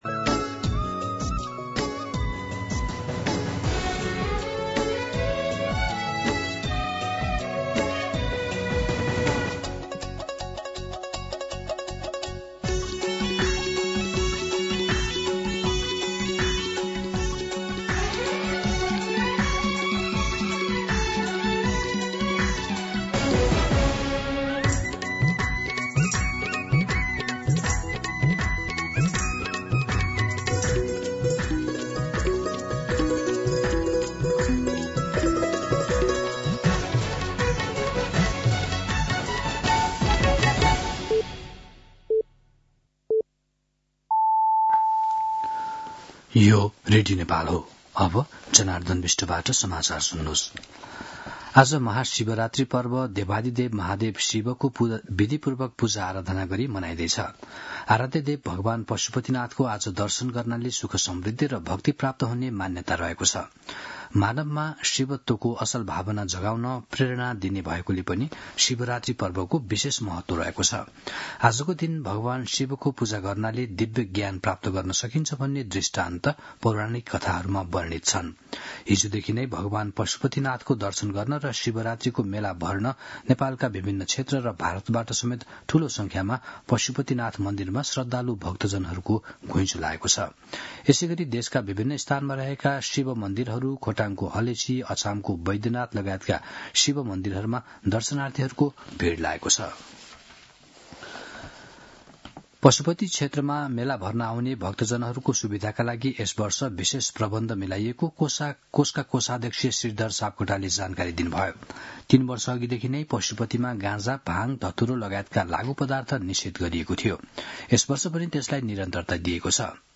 मध्यान्ह १२ बजेको नेपाली समाचार : ३ फागुन , २०८२